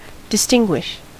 Ääntäminen
IPA : /dɪsˈtɪŋɡwɪʃ/